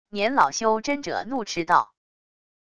年老修真者怒斥道wav音频